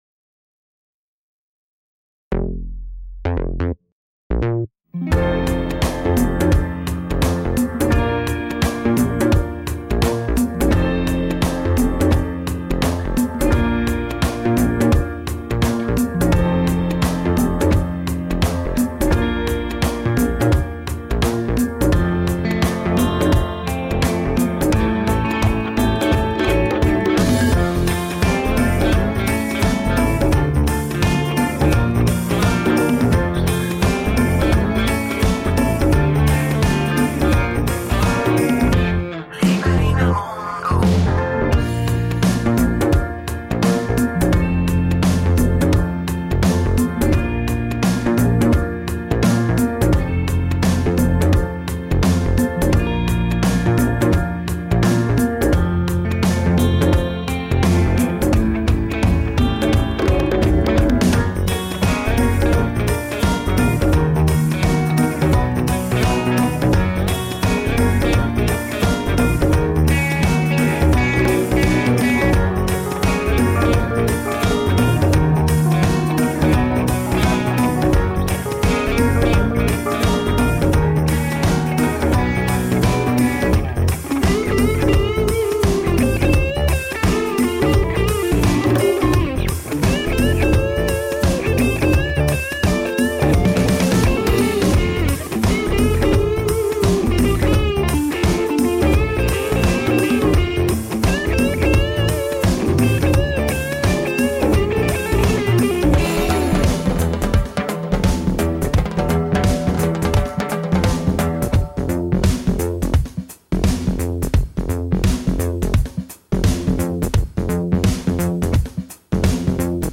Relaxed pop and reggae music made in italy.